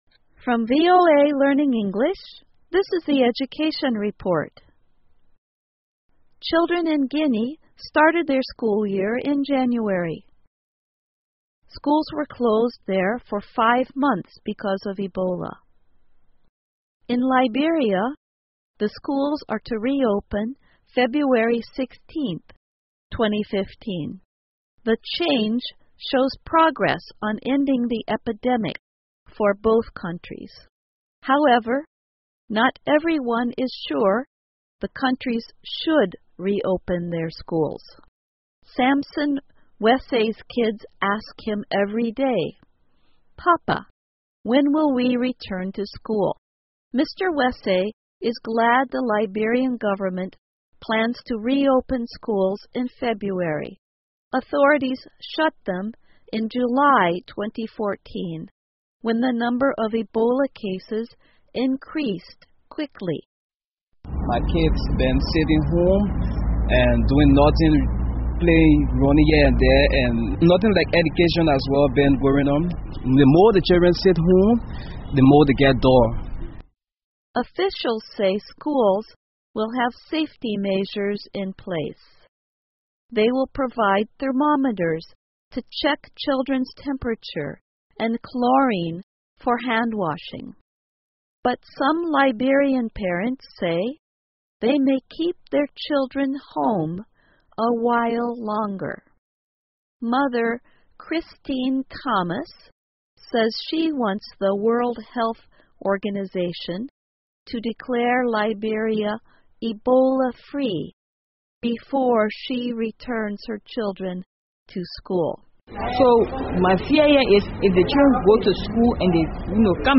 VOA慢速英语2015 几内亚和利比里亚由于埃博拉关闭的学校重新开学 听力文件下载—在线英语听力室